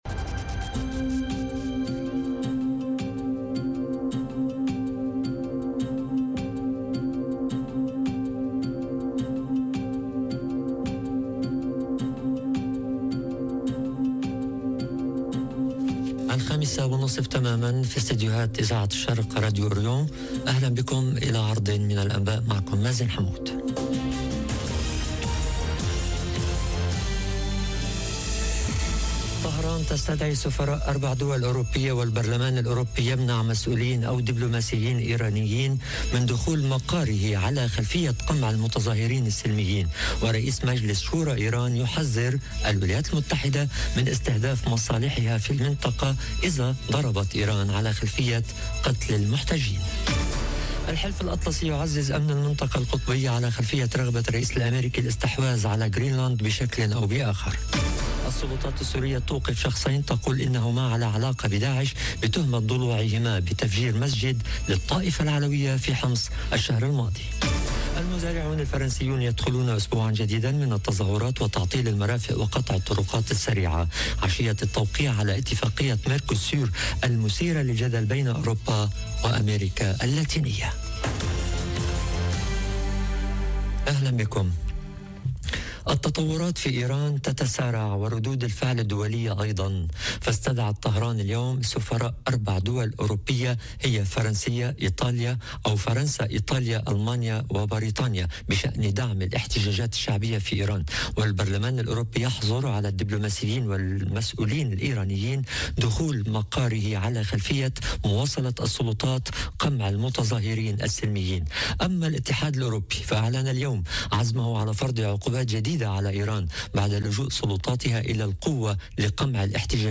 نشرة أخبار المساء: طهران تستدعي سفراء اربع دول أوروبية والبرلمان الأوروبي يمنع مسؤولين او دبلوماسيين إيرانيين من دخول مقاره على خلفية قمع المتظاهرين السلميين - Radio ORIENT، إذاعة الشرق من باريس